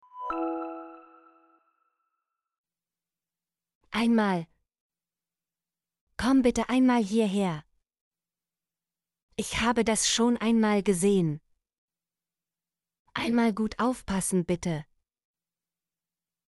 einmal - Example Sentences & Pronunciation, German Frequency List